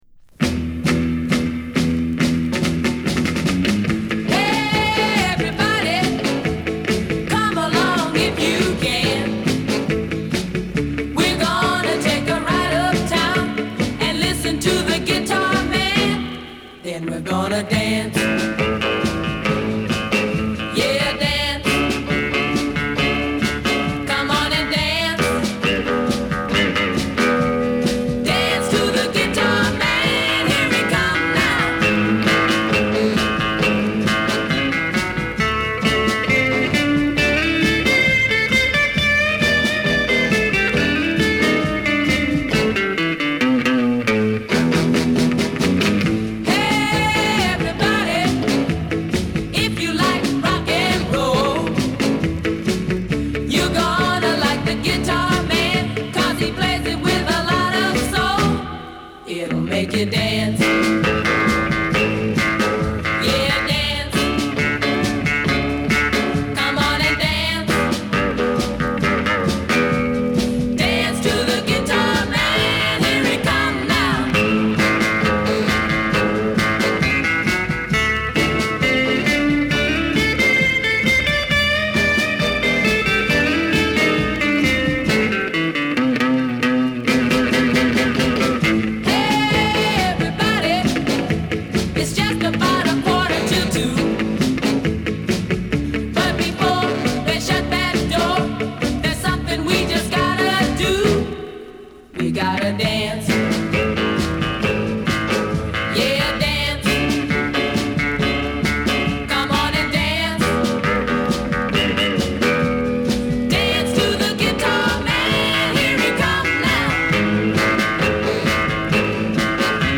録音状況も良く、ギターは勿論のこと各パートの演奏が際立って聴こえる。